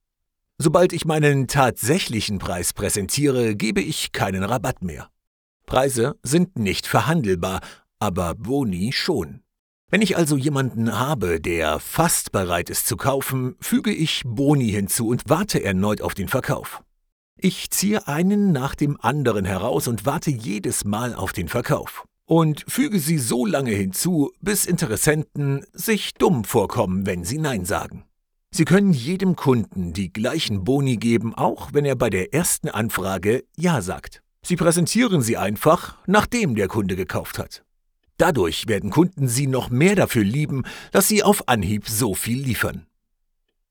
Hörbücher
Ich klinge dynamisch, frisch, kraftvoll, cool und selbstbewusst und bin bereit, rund um die Uhr in meinem Studio aufzunehmen – auch per Remote: Source Connect Now, Session Link Pro, ipDTL, Skype, Microsoft Teams.
Mikrofon: Neumann TLM 49